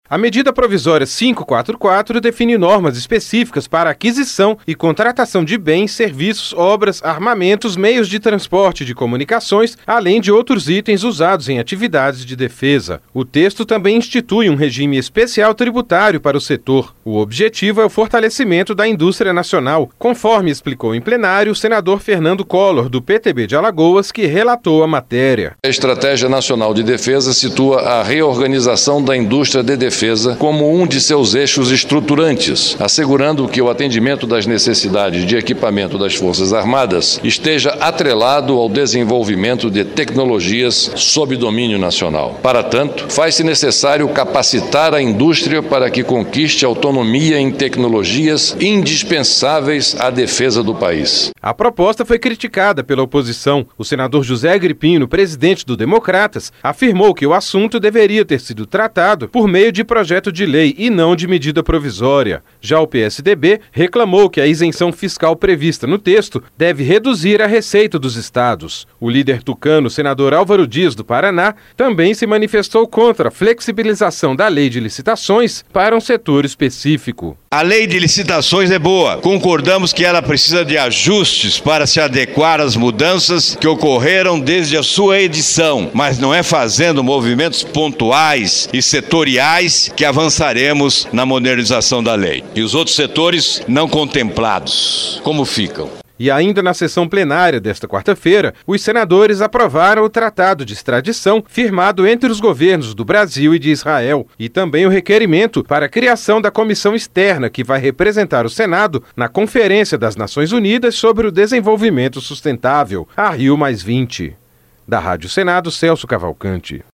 Além disso, institui um regime especial tributário para o setor. O objetivo é o fortalecimento da indústria nacional, conforme explicou em plenário o senador Fernando Collor, do PTB de Alagoas, que relatou a matéria.